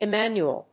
音節Em・man・u・el 発音記号・読み方
/ɪmˈænjuəl(米国英語), ɪˈmænju:ʌl(英国英語)/